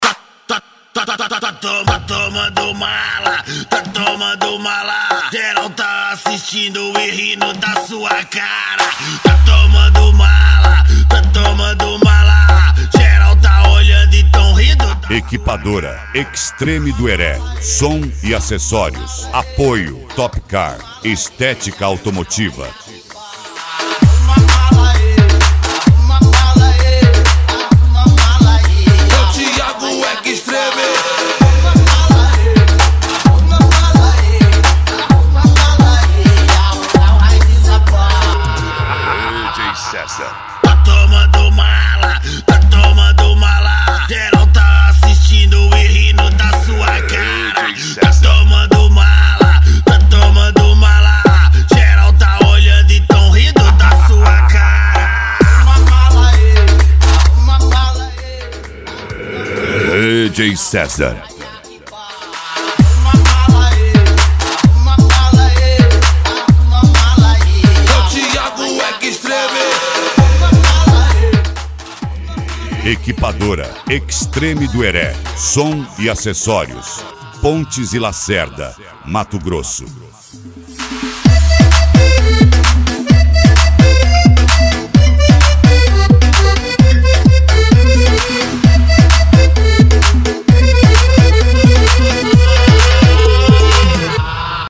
Deep House
Funk
Mega Funk
SERTANEJO